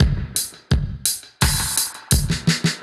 Index of /musicradar/dub-designer-samples/85bpm/Beats
DD_BeatA_85-02.wav